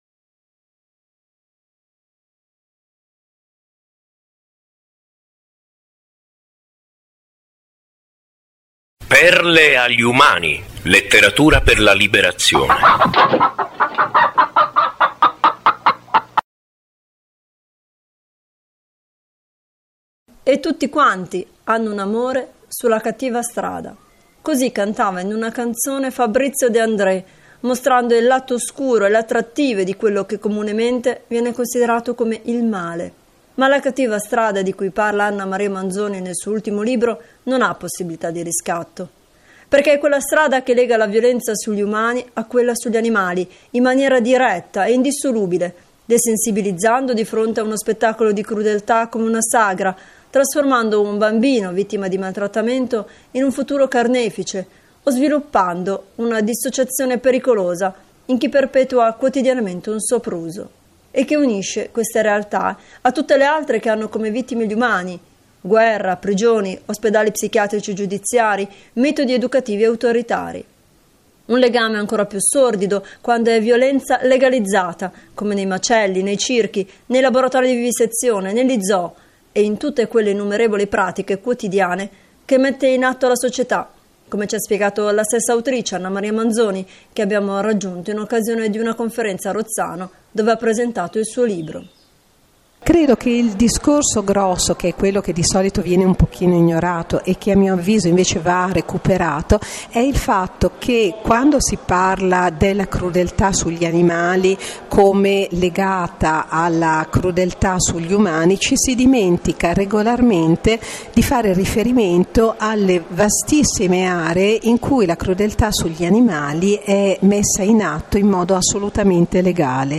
abbiamo intervistato l’autrice;